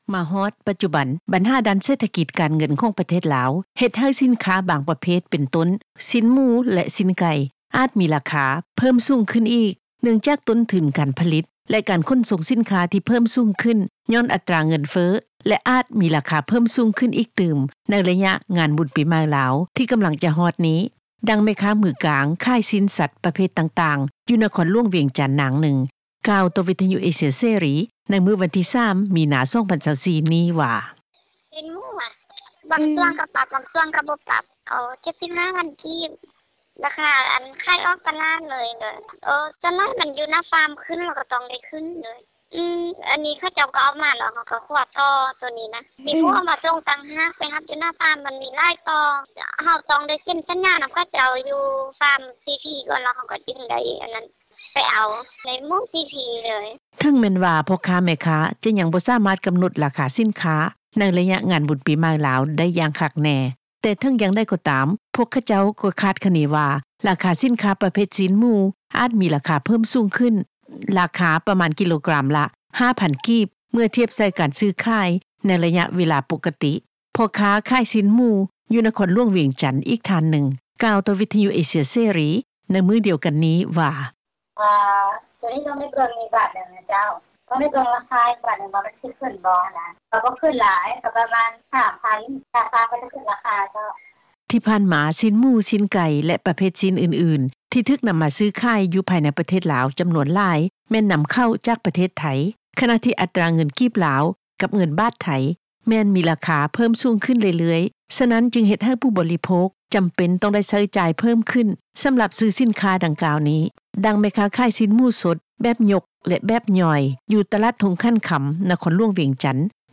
ດັ່ງ ແມ່ຄ້າຂາຍຊີ້ນສັດປະເພດຕ່າງໆ ຢູ່ນະຄອນຫລວງວຽງຈັນ ນາງນຶ່ງ ກ່າວຕໍ່ ວິທຍຸເອເຊັຽເສຣີ ໃນມື້ວັນທີ 3 ມີນາ 2024 ນີ້ວ່າ: